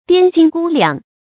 掂斤估两 diān jīn gū liǎng
掂斤估两发音